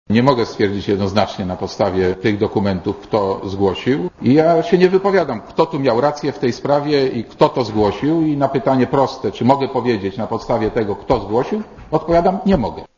Mówi Marek Borowski
"Białą księgę" przedstawił na konferencji prasowej marszałek Sejmu Marek Borowski.